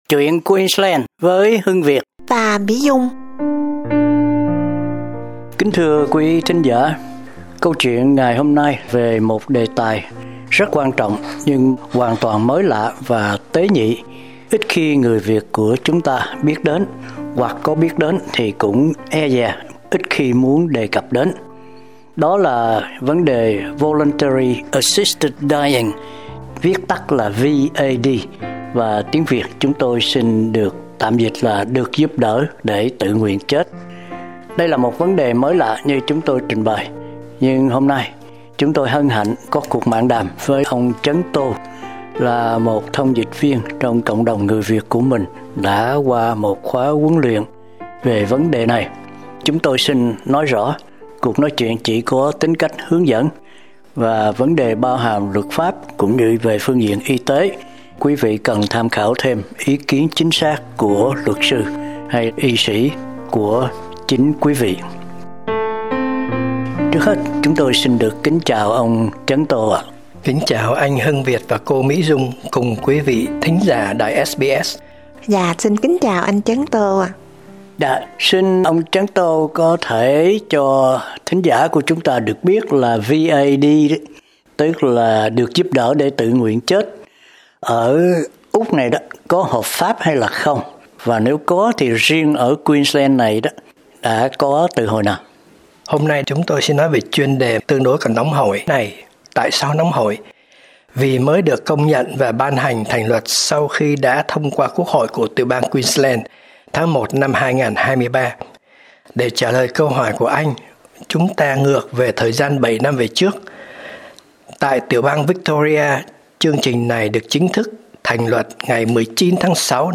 Kính mời quý thính giả theo dõi cuộc nói chuyện giữa hai phóng viên SBS